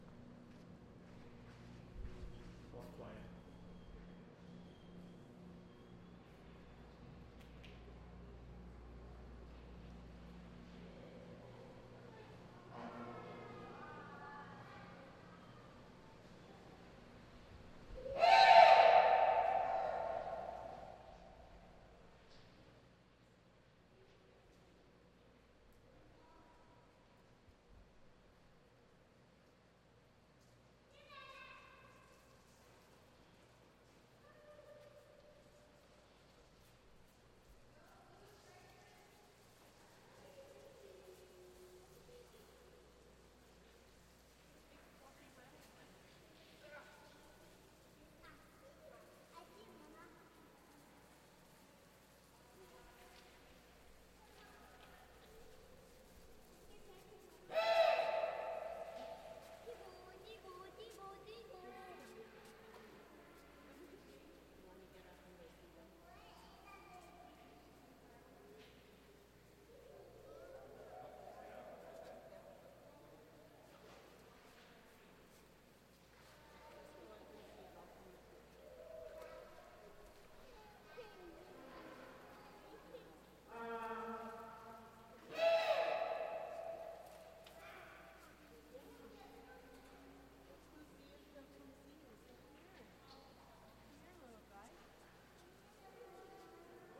卡尔加里之声 " 动物园的企鹅展览
描述：动物园企鹅展览：企鹅展览氛围/气氛，企鹅鸣喇叭，孩子们聊天 日期：2015年11月21日时间：上午11:18记录编号：T56位置：加拿大阿尔伯塔省卡尔加里卡尔加里动物园的企鹅展览技术：录制声音设备录音机和Rode NT4霰弹枪麦克风。
Tag: 企鹅 atmopshere 混响 氛围 卡尔加里 动物园 鸣喇叭